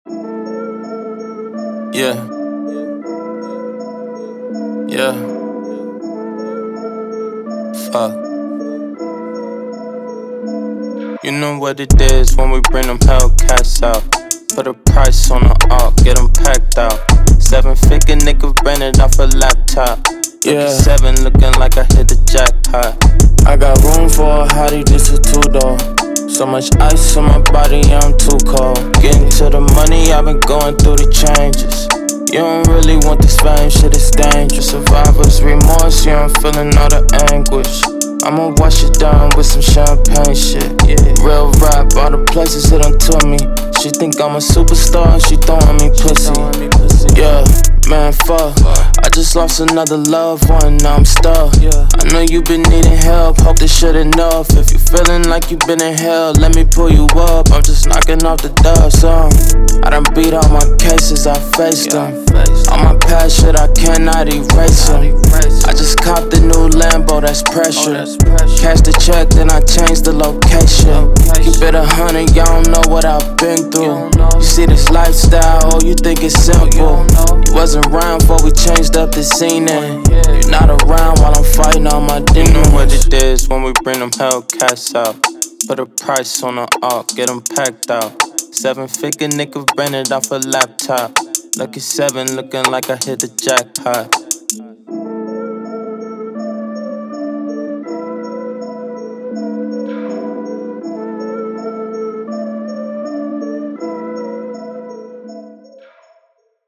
Hip Hop
A minor